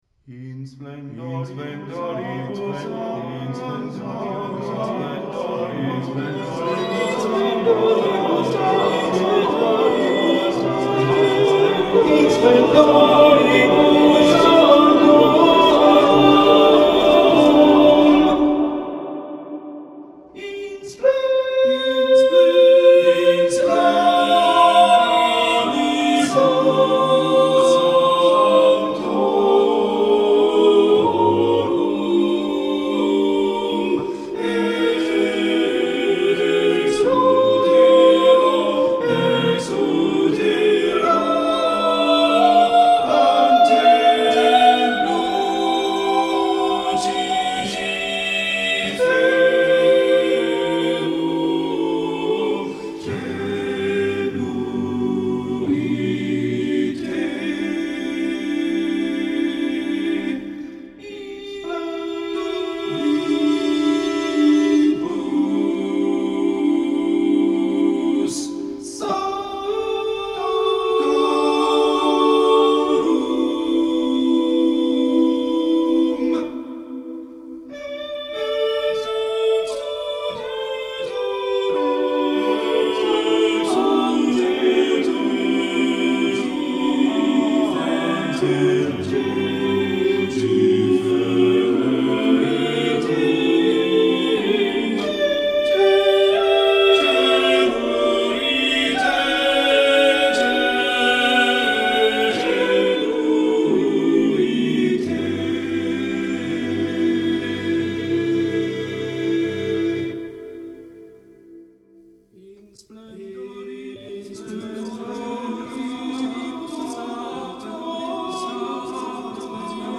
File File history File usage 2._In_splendoribus_sanctorum.mp3  (file size: 2.71 MB, MIME type: audio/mpeg ) Summary By composer in studio.
Removed sound glitch 23:19